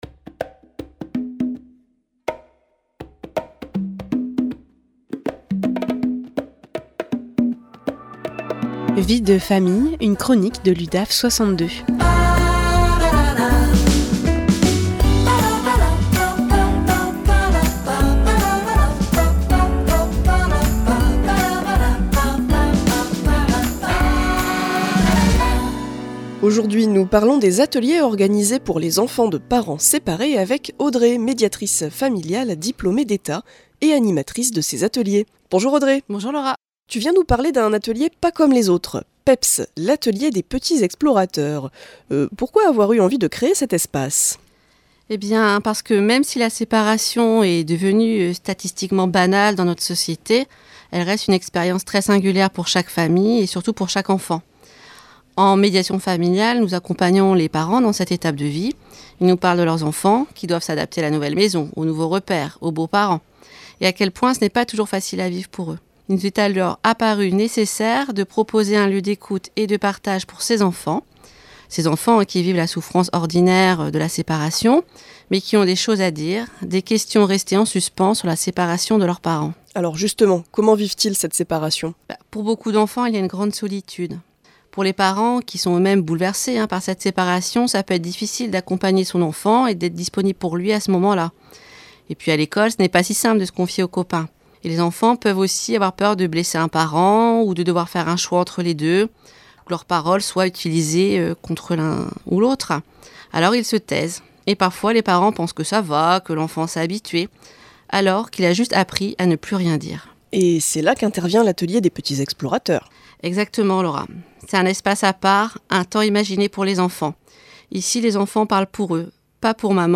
Les professionnels de l’Udaf 62 interviennent au micro de PFM Radio à Arras, en proposant des chroniques sur divers sujets en lien avec leurs services respectifs.
Vie de Famille, une chronique de l’Udaf62 en live sur RADIO PFM 99.9